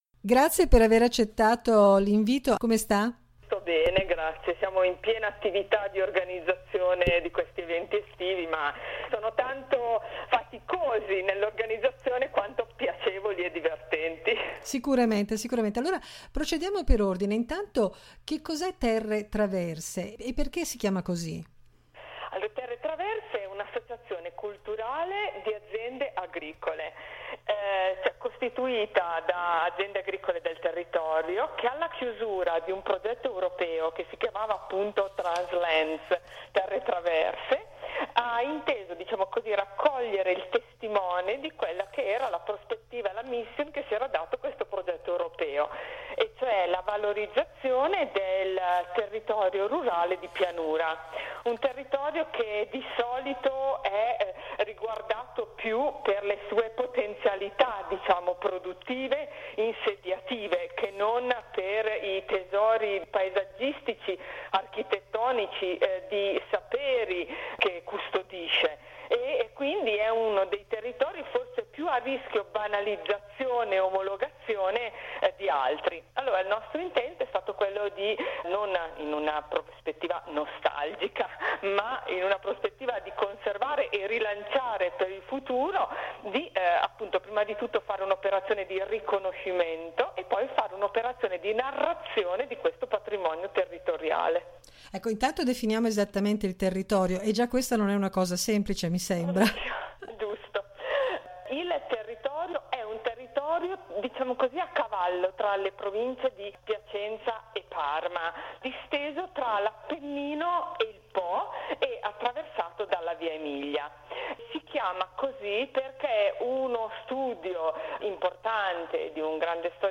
chiacchierata